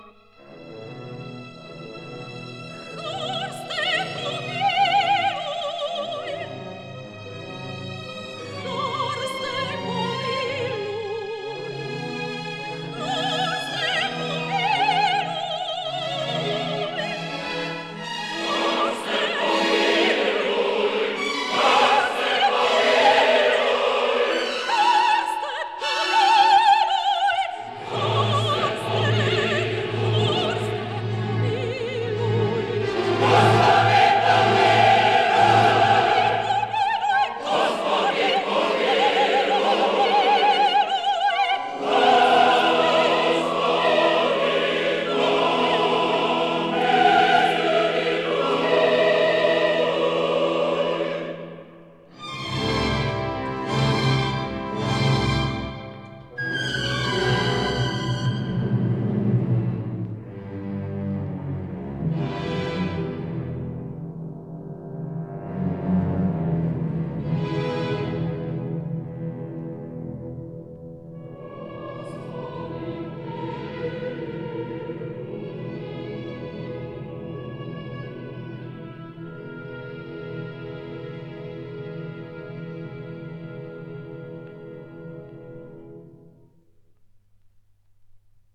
soprano
alto
tenor
bass
organ
Stereo recording made in Dvořák Hall, Prague 22- 24 May 1961